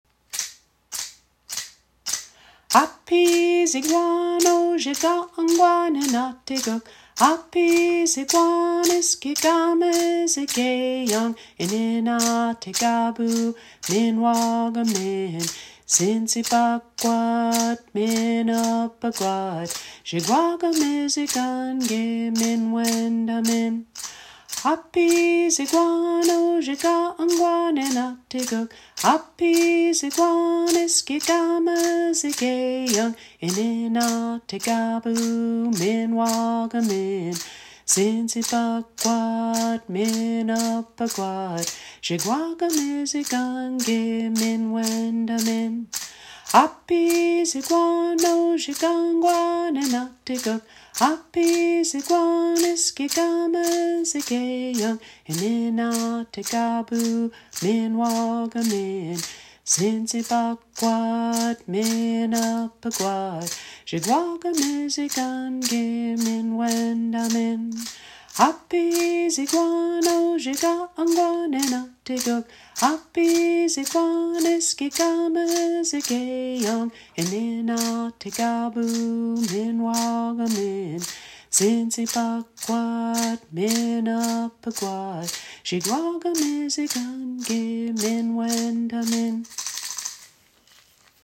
Spring is the time of the Anishinaabe New Year and the time to recognize the gifts we have been given. This song was created by the Swamp Singers after several of us spent time gathering and boiling sap in 2013.